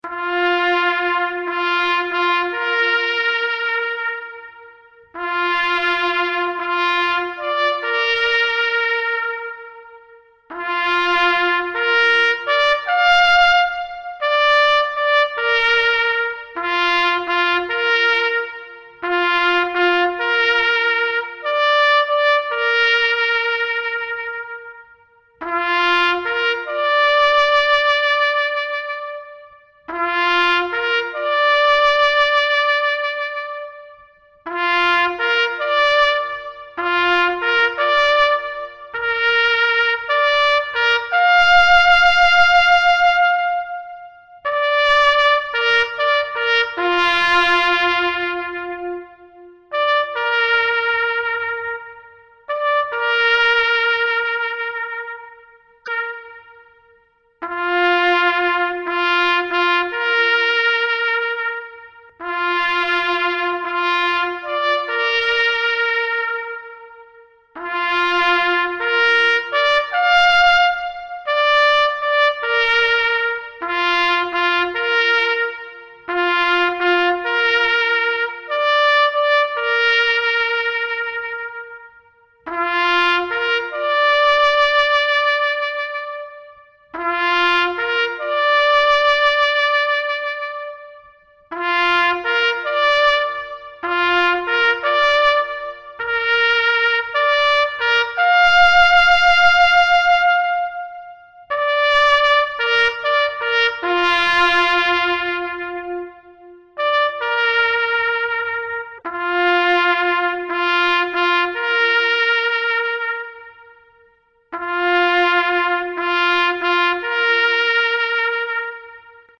"Ti" Sesi (2 Dakikalık)
10 Kasım gibi özel törenler için sadece 2 dakikalık, "ti" sesi vardır.
saygi_durusu_2_dk.mp3